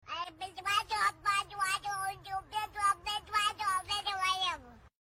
Cute Baby Arguing With Monkey Sound Effects Free Download